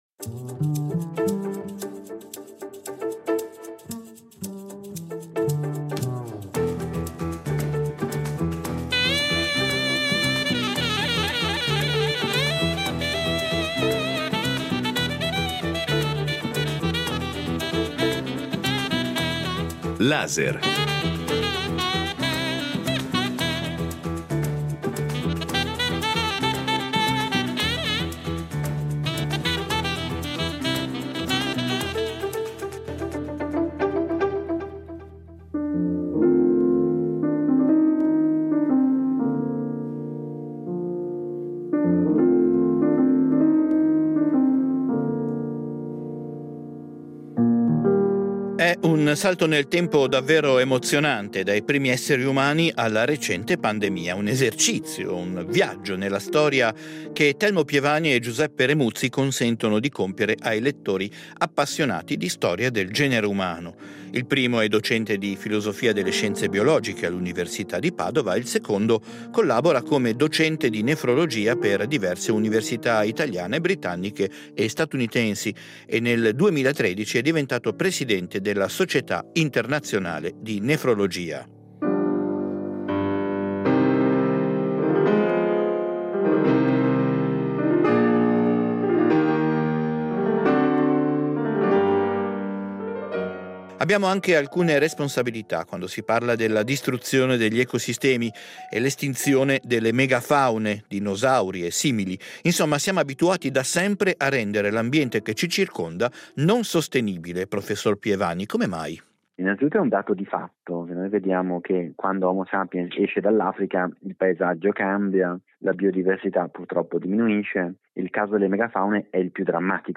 Colloquio con Telmo Pievani e Giuseppe Remuzzi
Perché siamo rimasti, allora, l’unica specie umana sul pianeta? Due tra i migliori scrittori di scienza italiani, un evoluzionista e un medico, ci illustrano le scoperte degli ultimi anni, gli enormi progressi sulla nostra storia, le conseguenze delle nostre azioni passate e presenti e perché siamo di nuovo, drammaticamente a rischio estinzione.